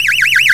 TSIREN.WAV